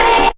Low_Organ.wav